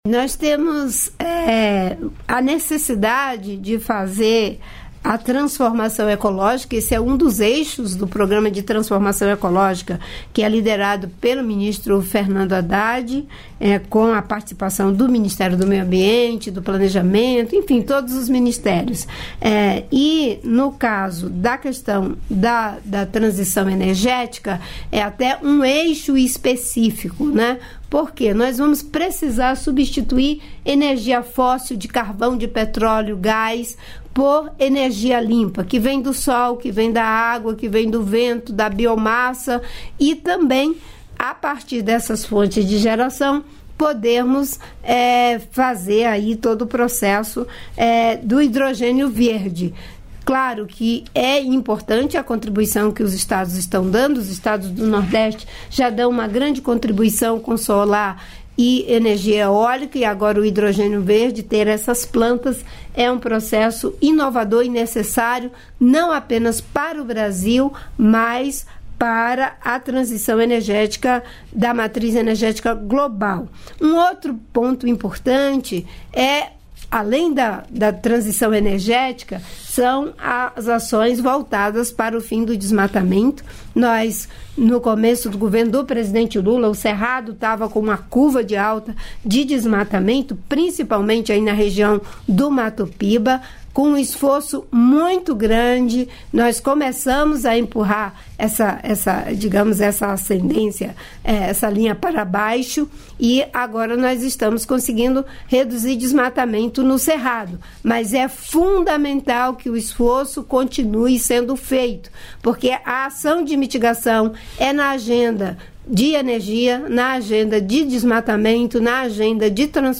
Trecho da participação ministra do Meio Ambiente e Mudança do Clima, Marina Silva, no programa "Bom Dia, Ministra" desta segunda-feira (05), nos estúdios da EBC em Brasília (DF).